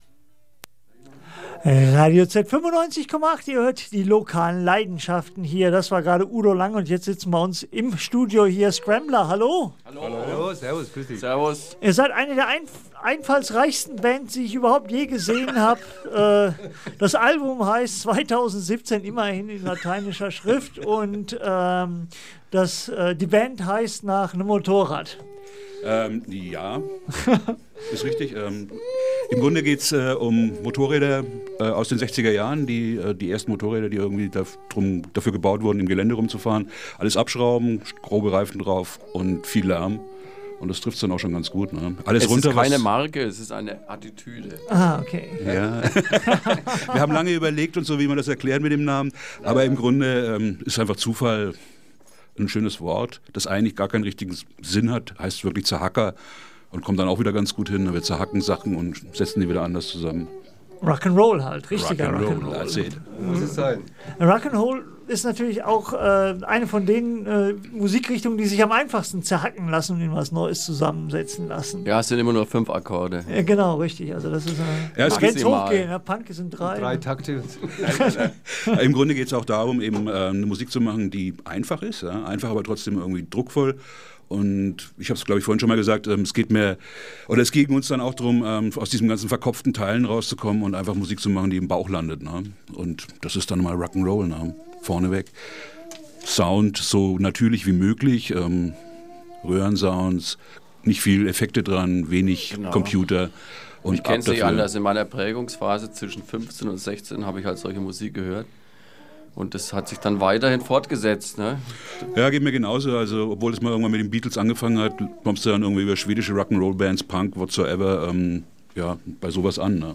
Das Interview
Unsere Songs haben wir da so reingepackt wie on air, viel Spaß beim Zuhören!
RadioZ-Interview-Scrambler1.mp3